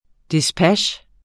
Udtale [ disˈpaɕ ]